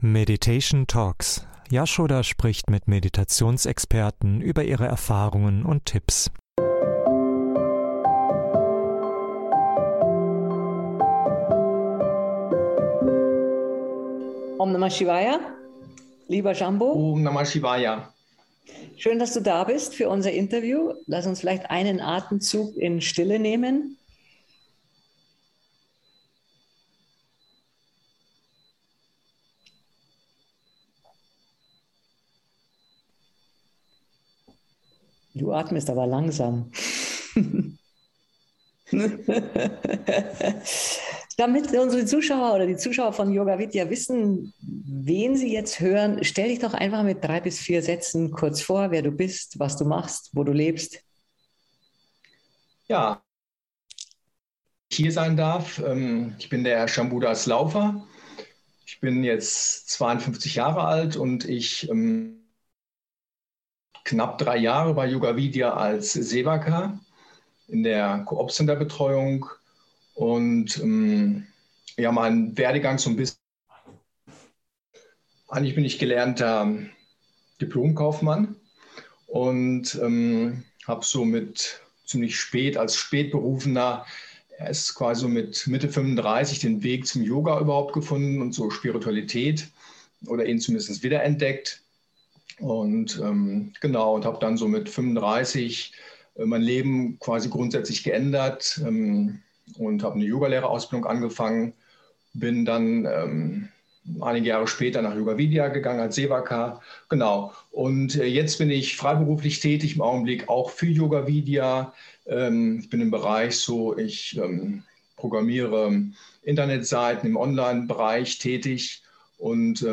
So unterstützen dich die Interviews bei der Vertiefung deiner eigenen Meditationspraxis.